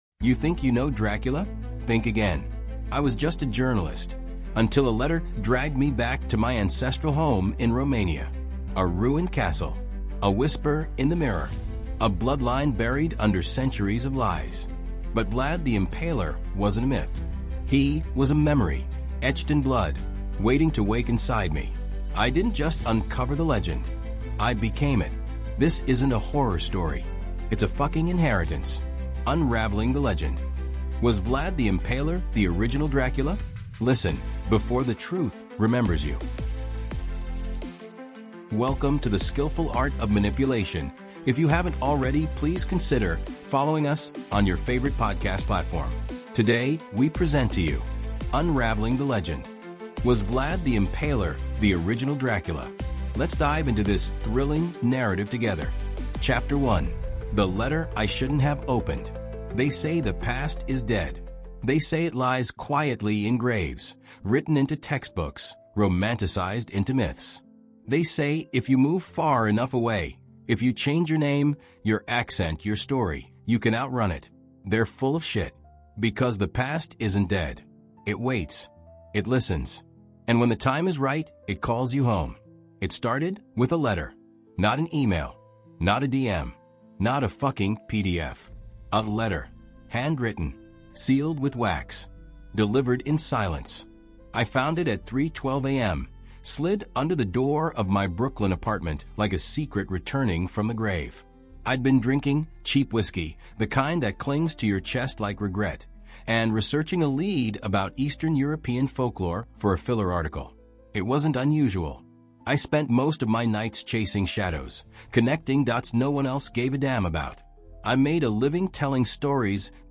Unraveling the Legend: Was Vlad the Impaler the Original Dracula? | Audiobook